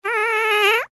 Звуки пердежа, пука
Звук неудачного шептуна (00:01)
фейл